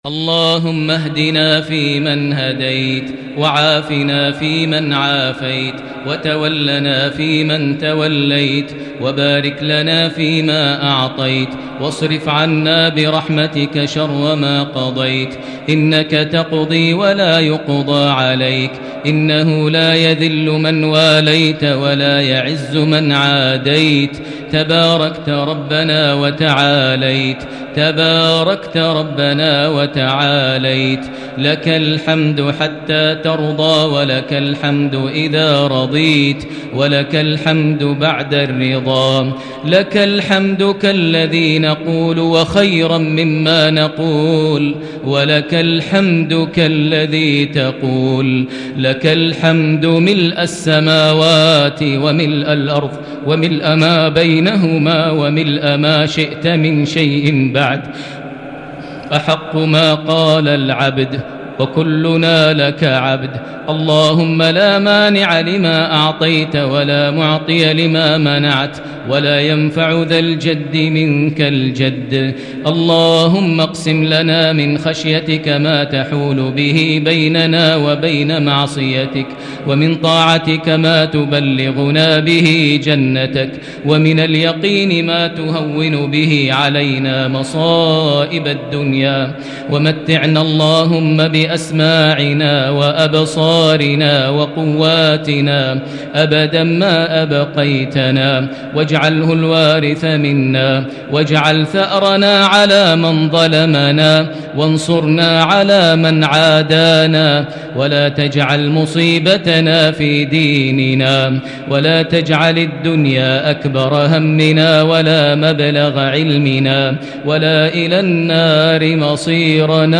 دعاء ليلة 8 رمضان 1441هـ > تراويح الحرم المكي عام 1441 🕋 > التراويح - تلاوات الحرمين